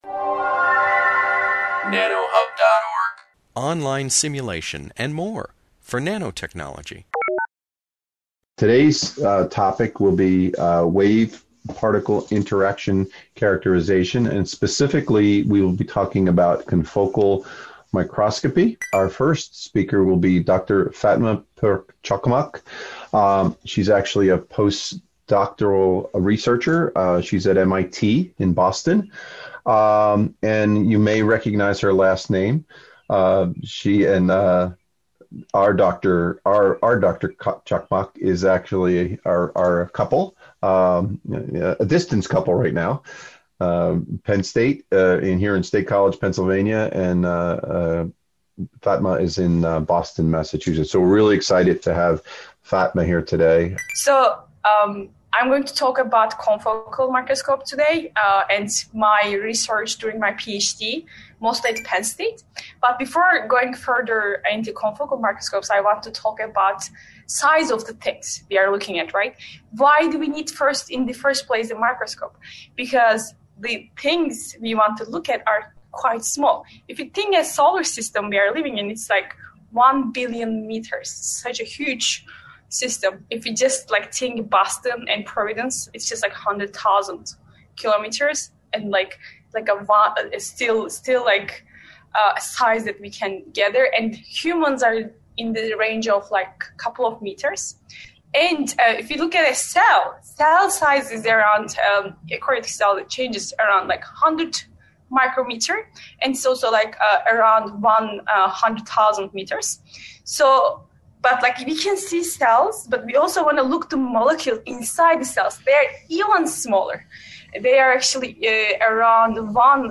This webinar, published by the Nanotechnology Applications and Career Knowledge Support (NACK) Center at Pennsylvania State University, provides an introduction to confocal microscopy.